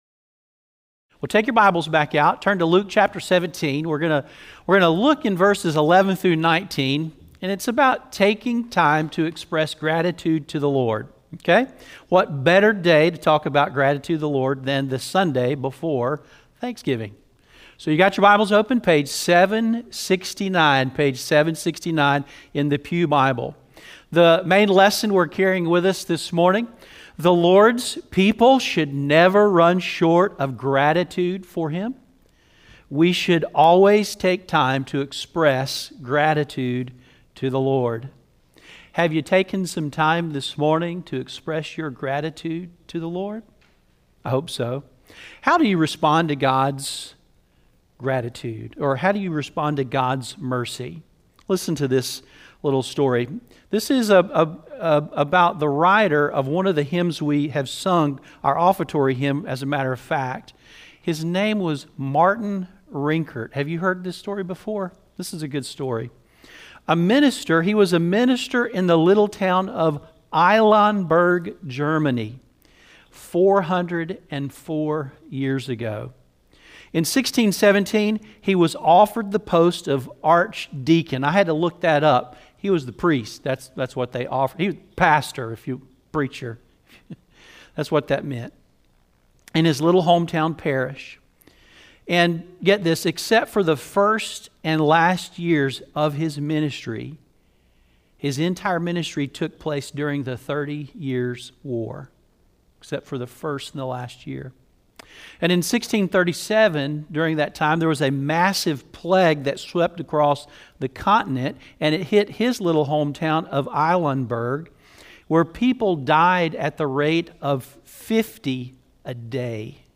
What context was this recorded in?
Service Audio